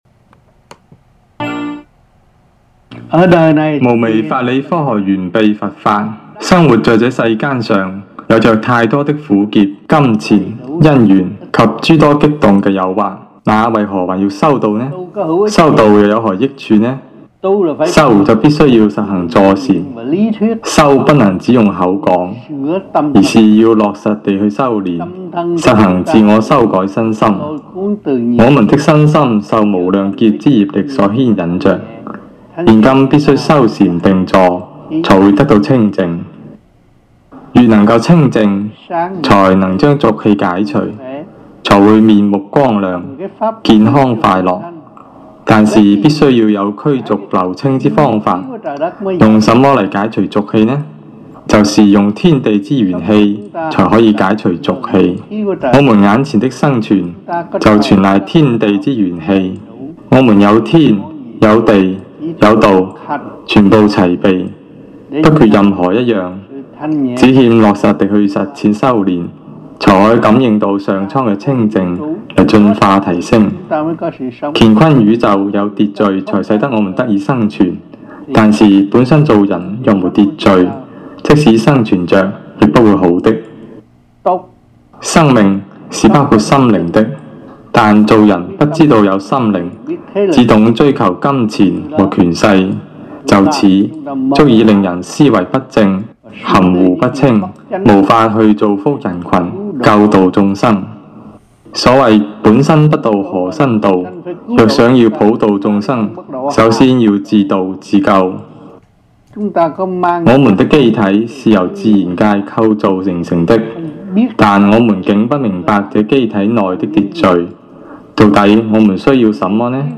Lectures-Chinese-1999 (中文講座)